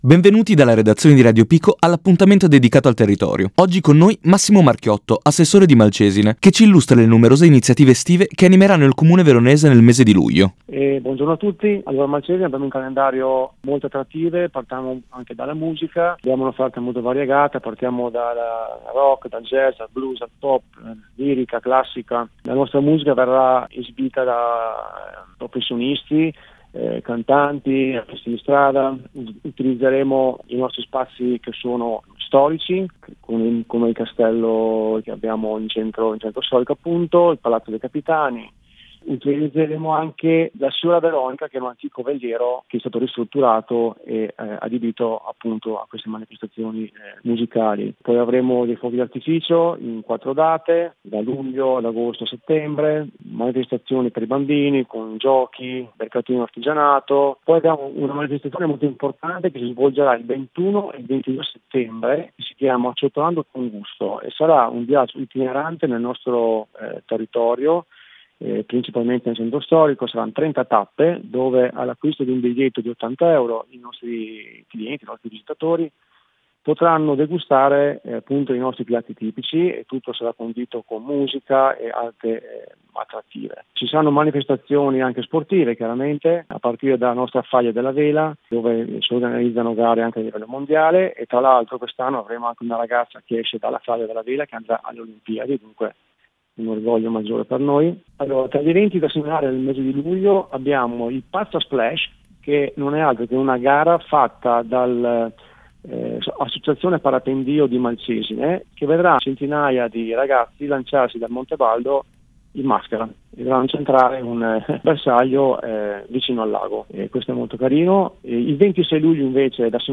Ai nostri microfoni Massimo Marchiotto assessore agli eventi di Malcesine: